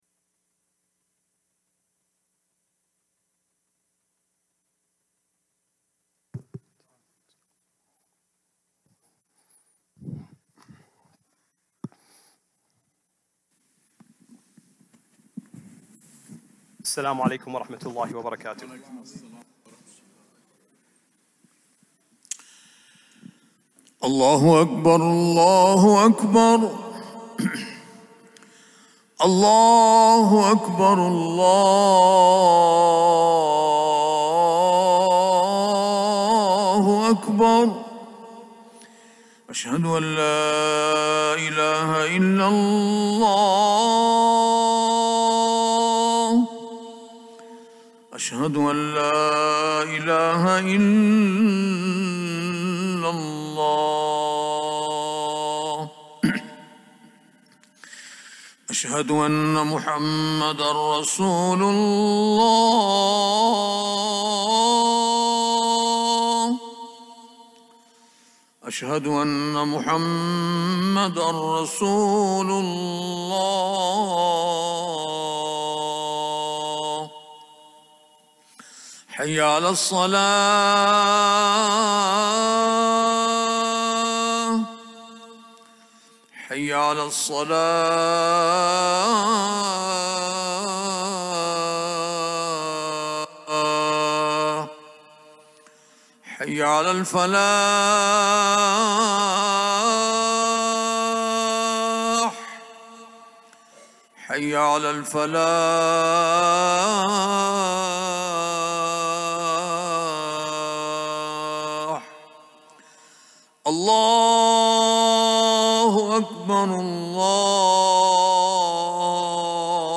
Friday Khutbah - "What I Have Seen"